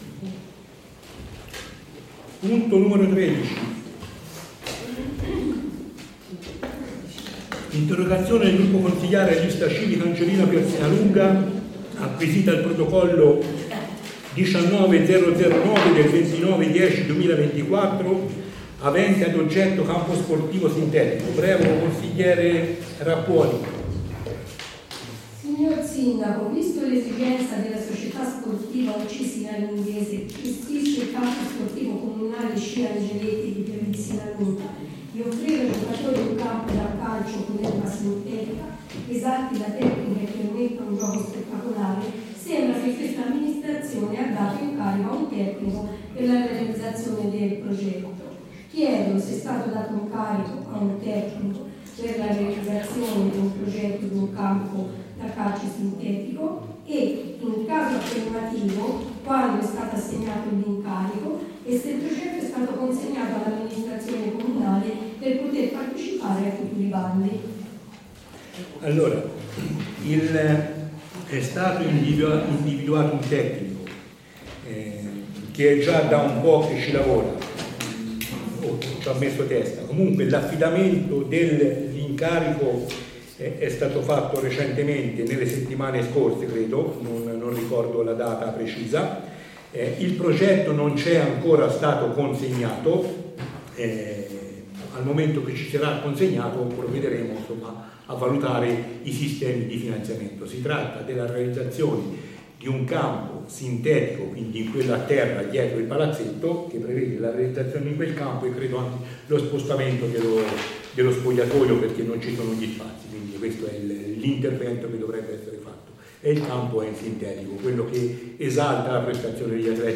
Registrazioni Consiglio - Audio 2024
Audio Consiglio del 28 Novembre 2024